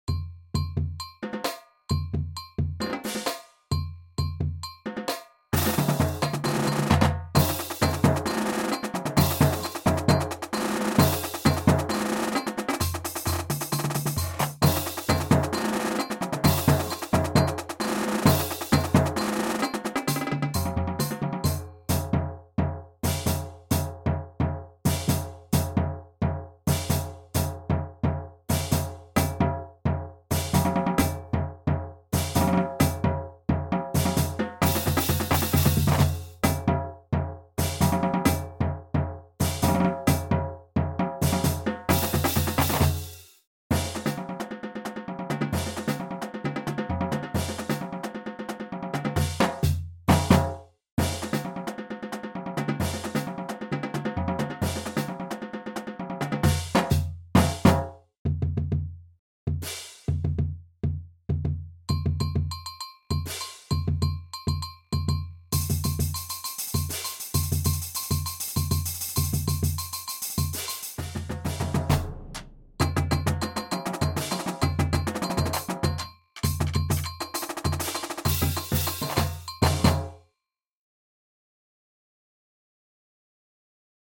Ongestemd Marcherend Slagwerk
Snare drum Percussion Quad Cymbals Bass drum